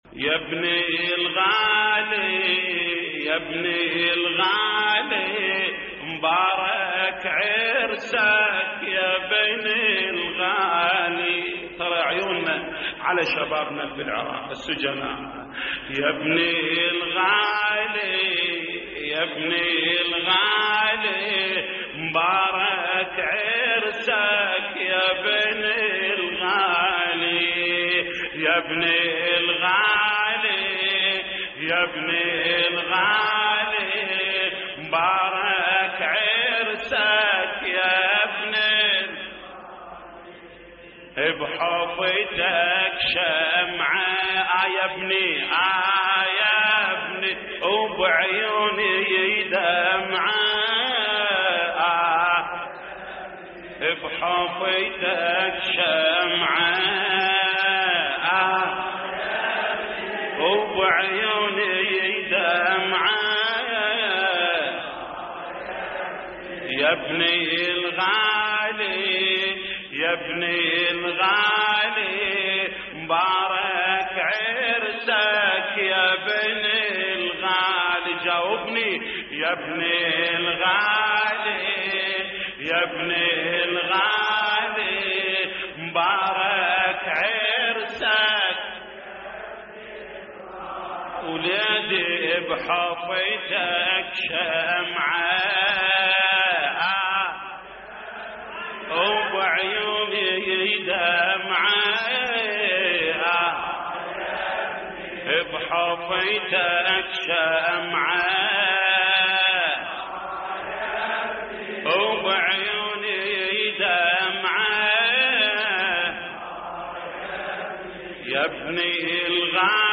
تحميل : يبني الغالي يبني الغالي مبارك عرسك يبني الغالي / الرادود جليل الكربلائي / اللطميات الحسينية / موقع يا حسين